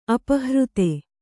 ♪ apahřte